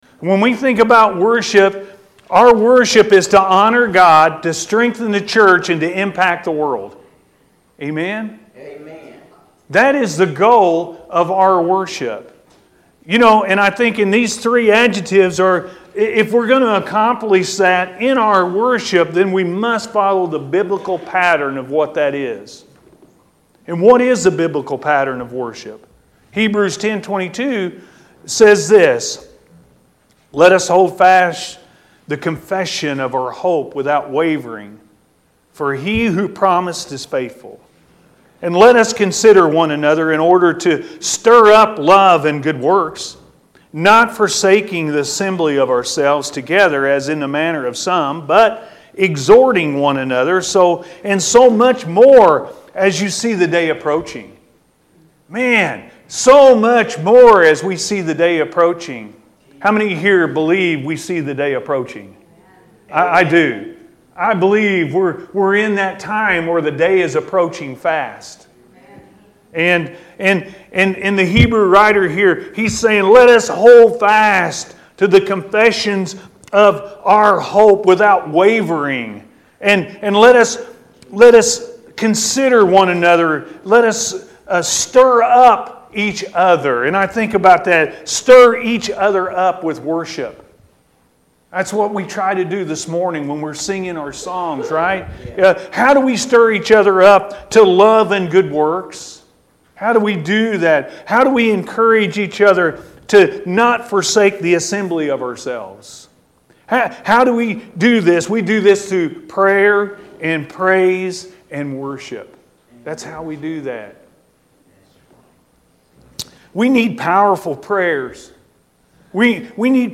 Worship-A.M. Service